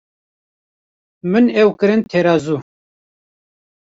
Pronounced as (IPA) /tɛɾɑːˈzuː/